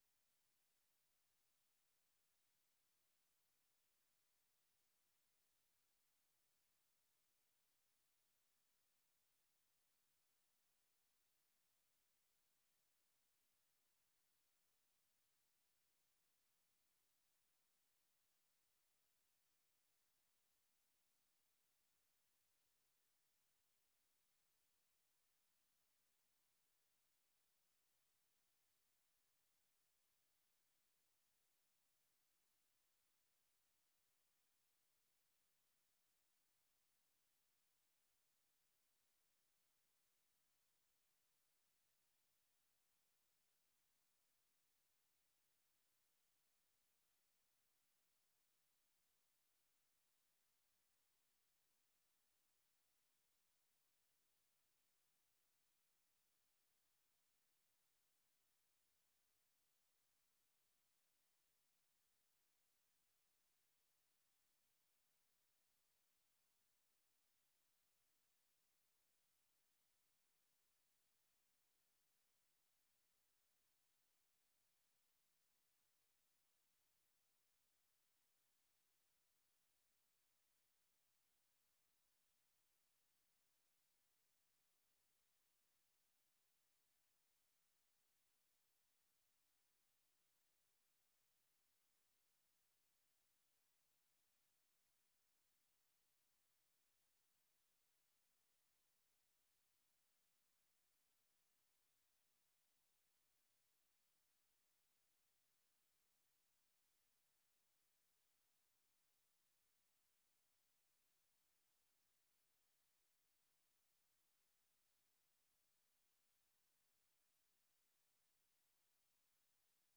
در برنامۀ روایت امروز شرح وضعیت در افغانستان را از زبان شرکت کنندگان این برنامه می‌شنوید. این برنامه شب‌های یک‌شنبه، دوشنبه، سه‌شنبه و پنج‌شنبه از ساعت ٩:۰۰ تا ۹:۳۰ شب به گونۀ زنده صدای شما را در رادیو و شبکه‌های ماهواره‌ای و دیجیتلی صدای امریکا پخش می‌کند.